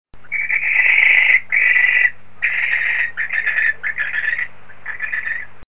Effraie des clochers
Tyto alba
effraie.mp3